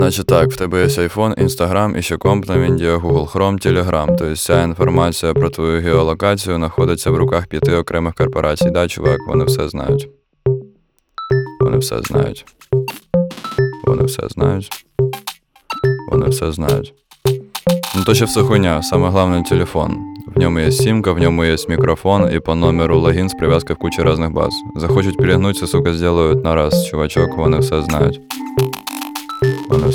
Жанр: Рэп и хип-хоп / Русские